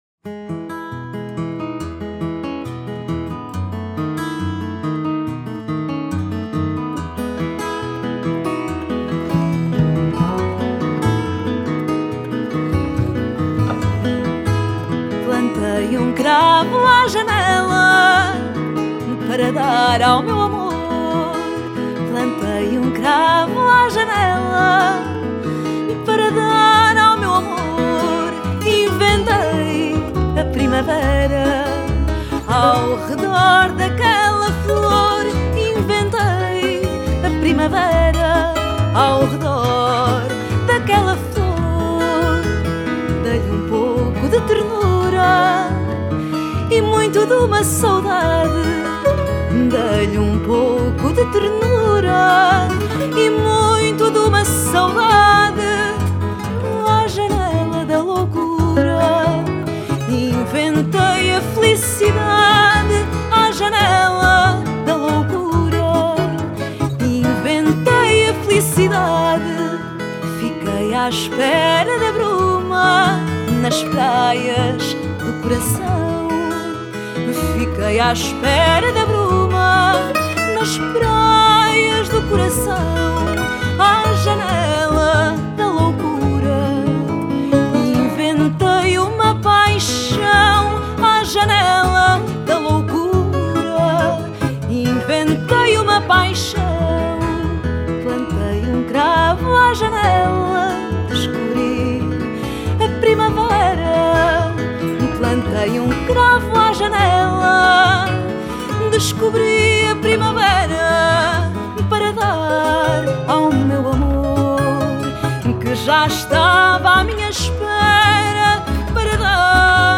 Genre: Fado